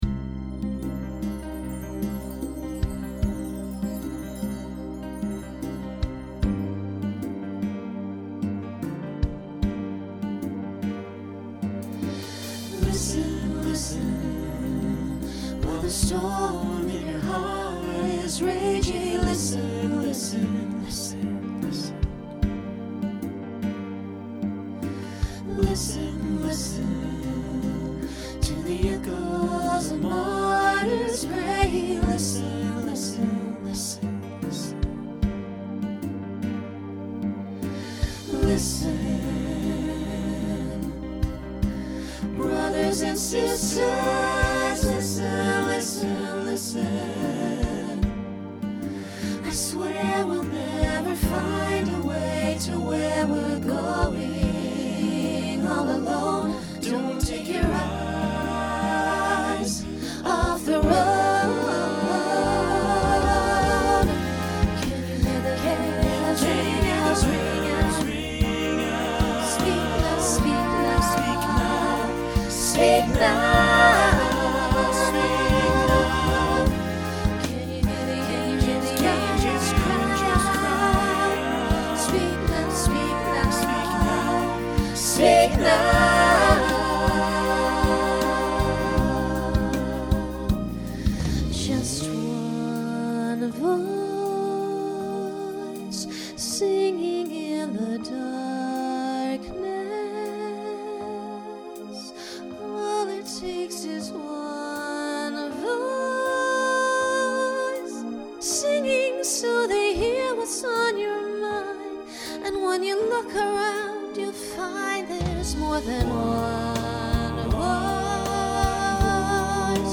Genre Pop/Dance
Function Ballad Voicing SATB